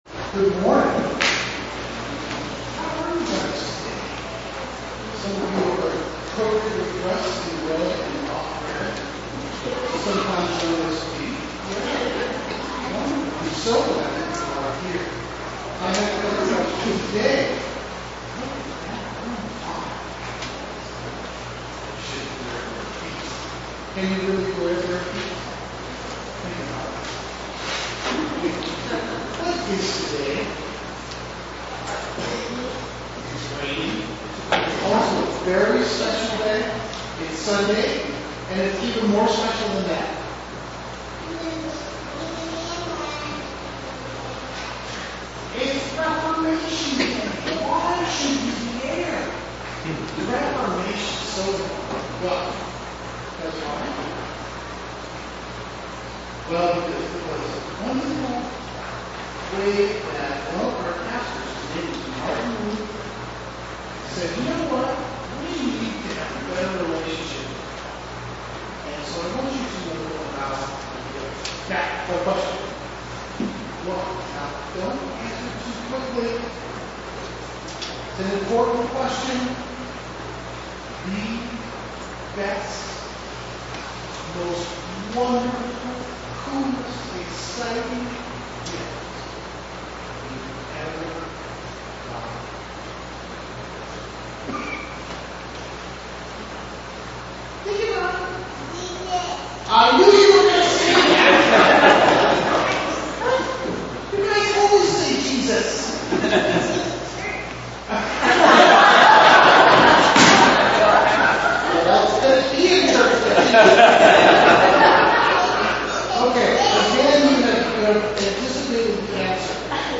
LCH Children’s Conversations—Time after Pentecost 2008 (October and November)